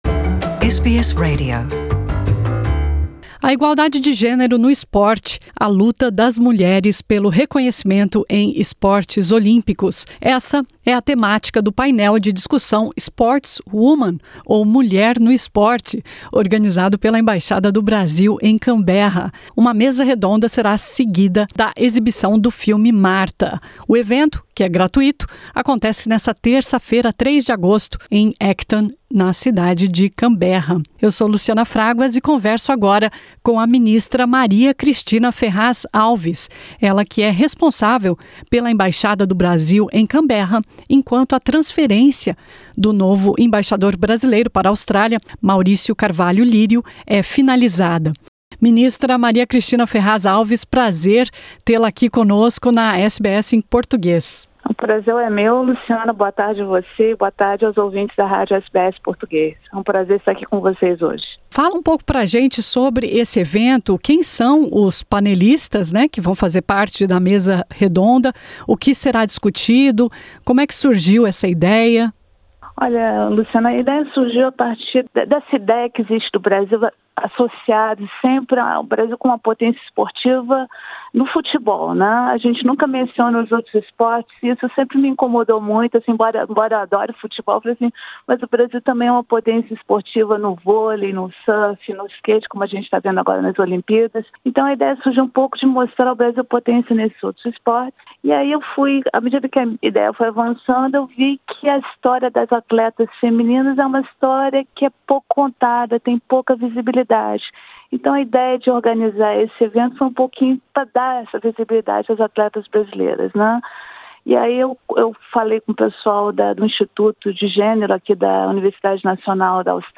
Ouça a entrevista completa com a Ministra Maria Cristina Ferraz Alves clicando no botão "play" na foto que abre a reportagem.